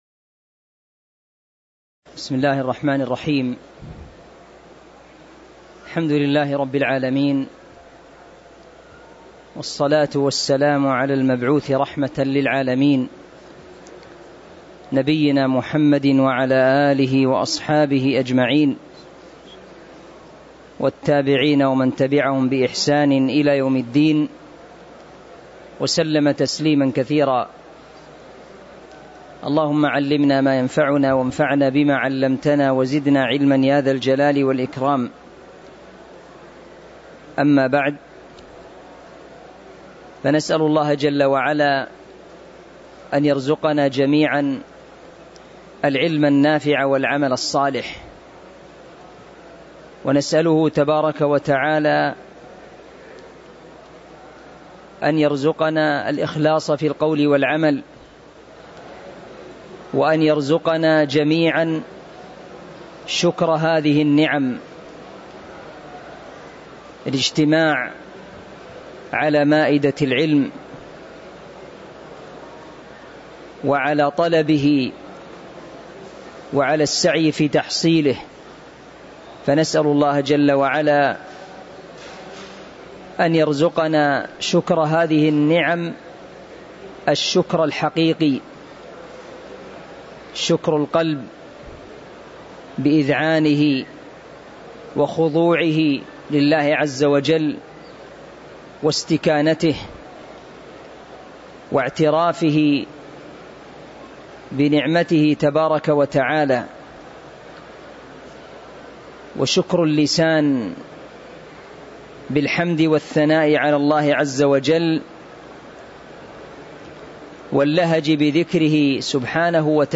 تاريخ النشر ١٧ محرم ١٤٤٦ هـ المكان: المسجد النبوي الشيخ